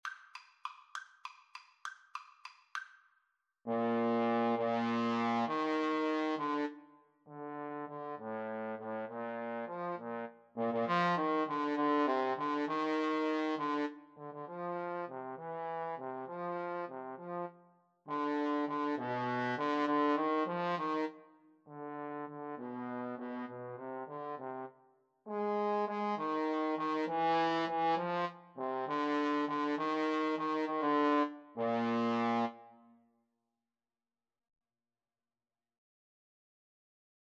3/8 (View more 3/8 Music)
Classical (View more Classical Trombone Duet Music)